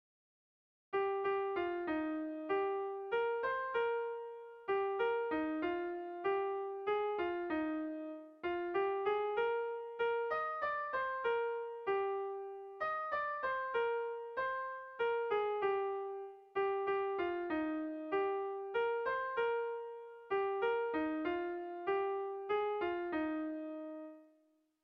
ABDAB